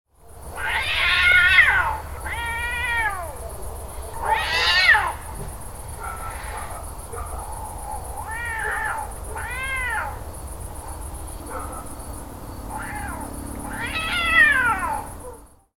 Cats-growling-at-each-other-sound-effect.mp3